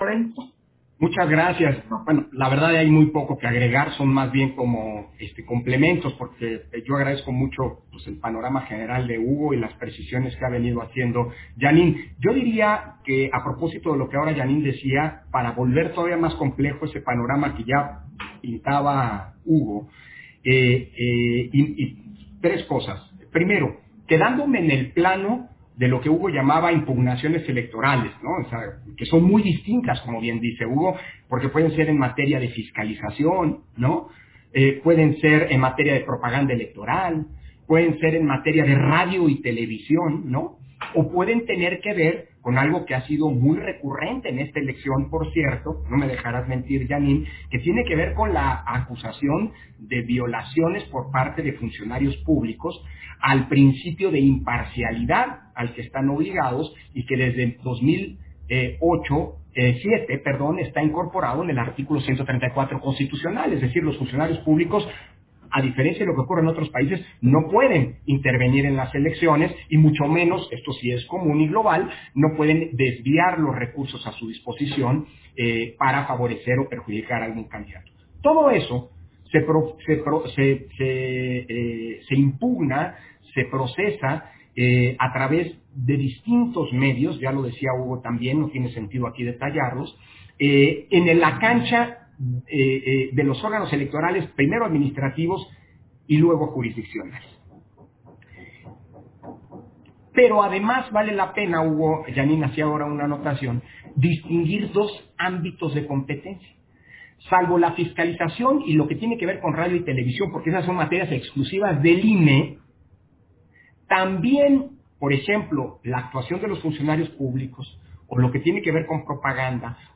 Intervenciones de Lorenzo Córdova, en la Mesa Redonda: Organización y Retos de la Elección